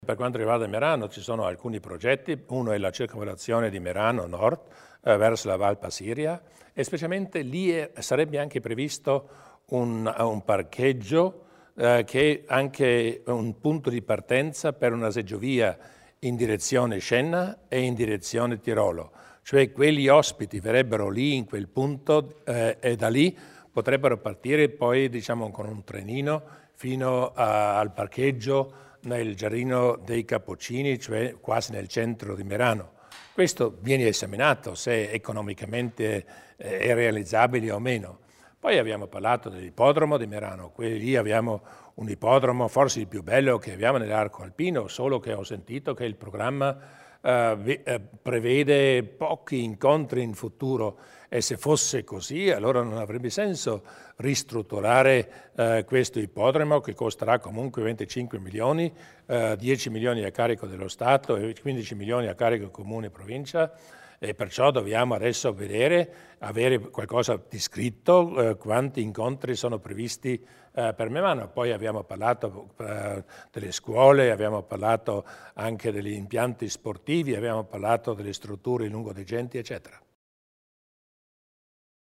Il Presidente Durnwalder spiega i progetti per la città di Merano